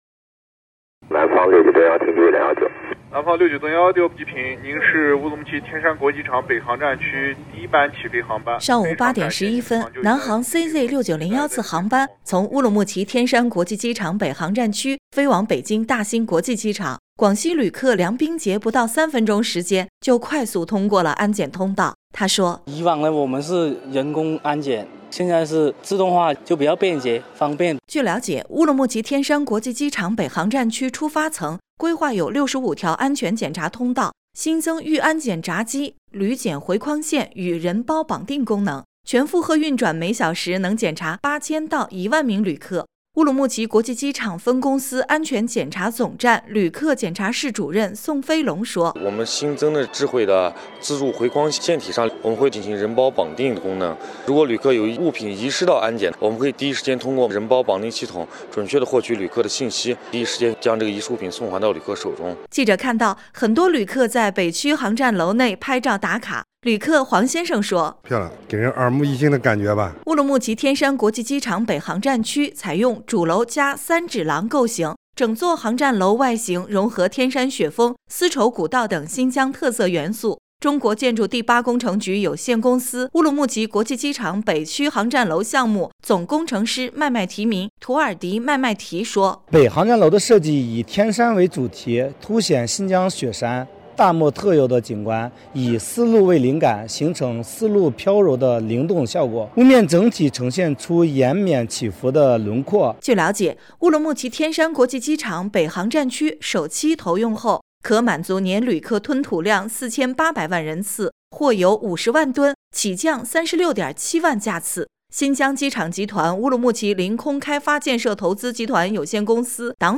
新闻消息： “新国门”启航 ——乌鲁木齐天山国际机场北航站区转场试运行